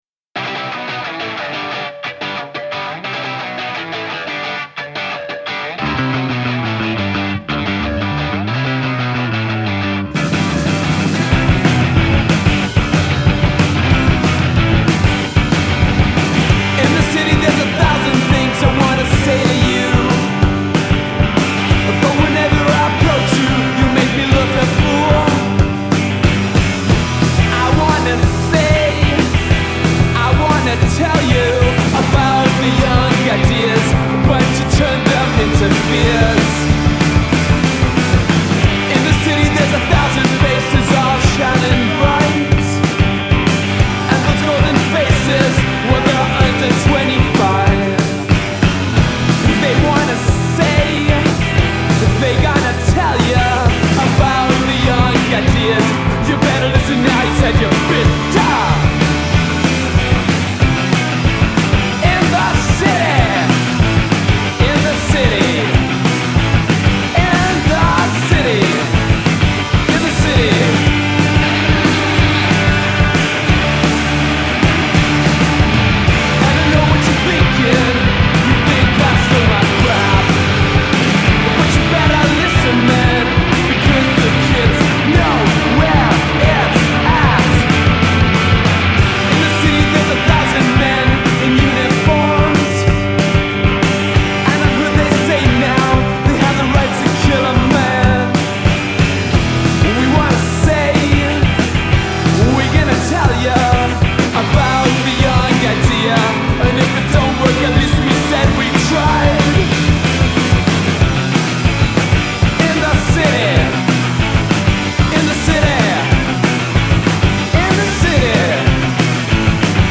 Una cover che capita quanto mai a proposito
con la loro consueta energia e appropriata urgenza